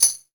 TAMB     MPC.wav